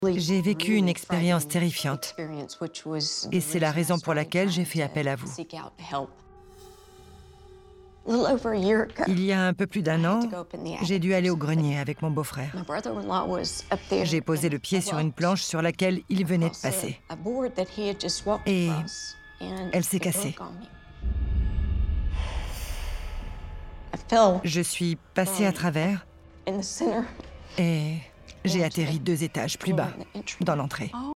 Timbre Chaud en voix-off
Timbre : Chaud Inquiète